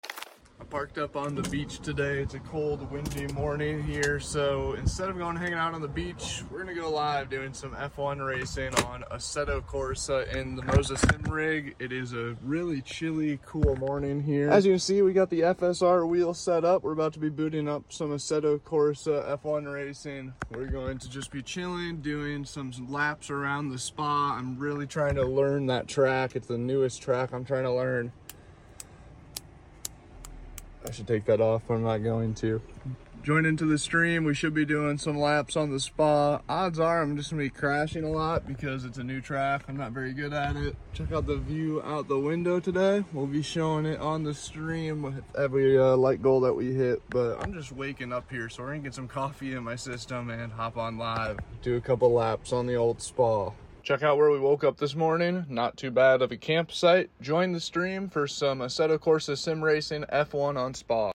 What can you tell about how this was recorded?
F1 at Spa on Assetto Corsa with the Moza & Next Level Racing racing simulator, in the van!